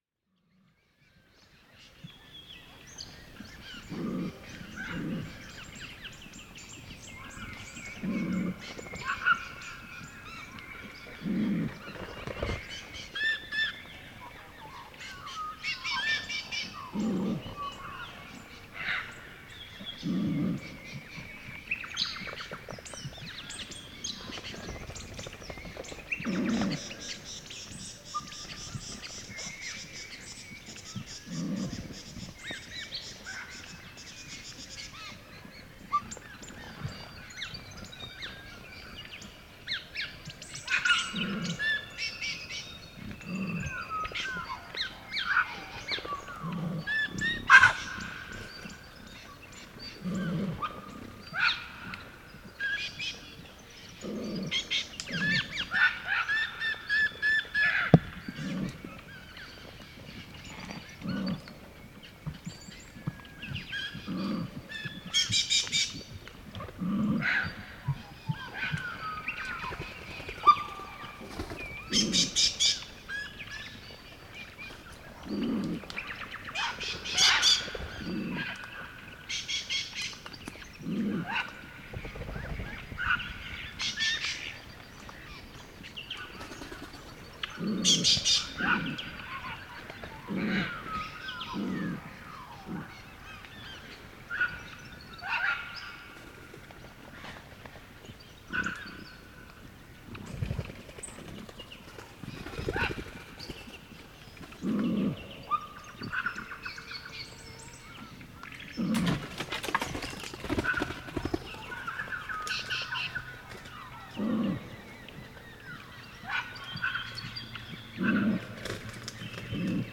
Звуки кенгуру
Звук схватки двух кенгуру в лесах Западной Австралии